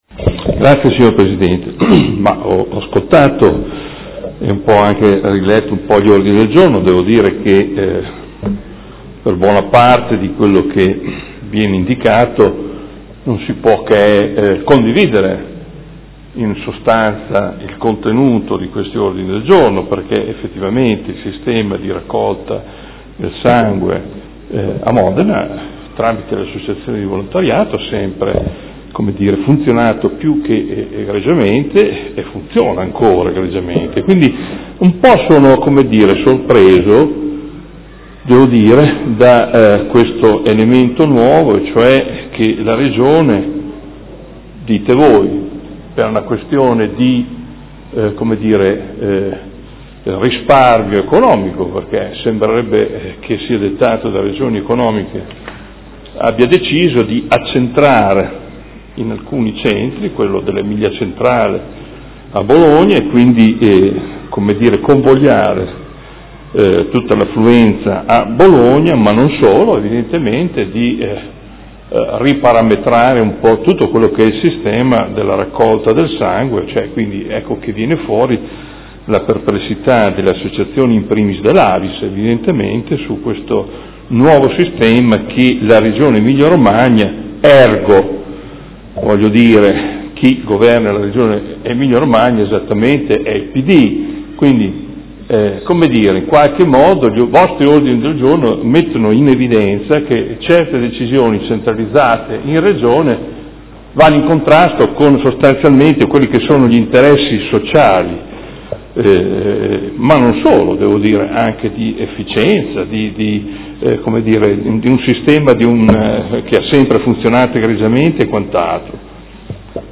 Seduta del 9/06/2016 Dibattito su Ordini del Giorno 46767 e 56194